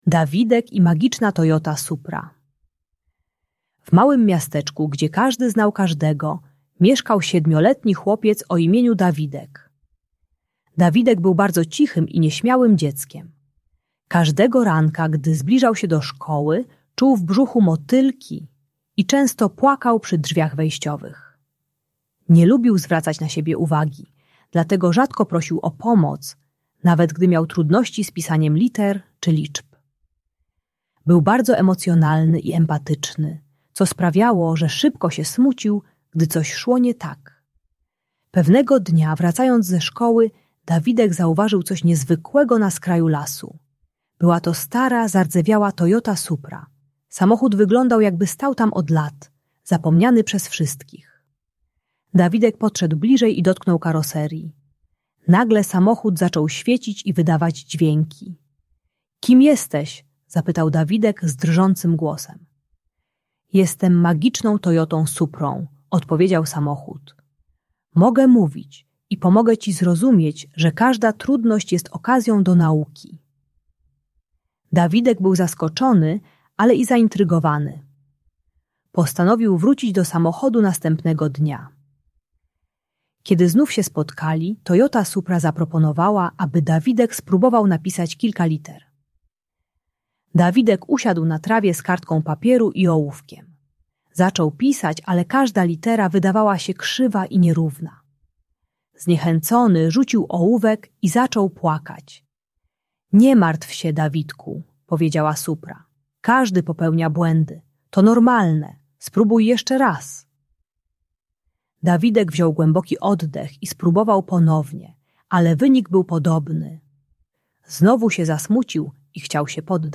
Historia Dawidka i Magicznej Toyoty Supry - Szkoła | Audiobajka